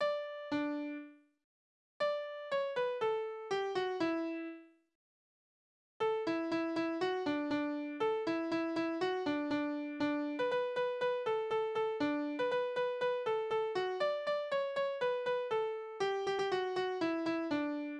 Lieder zu festlichen Gelegenheiten: Hochzeitslied
Tonart: D-Dur
Taktart: 4/4
Tonumfang: Oktave
Besetzung: vokal